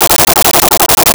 Arcade Movement 07.wav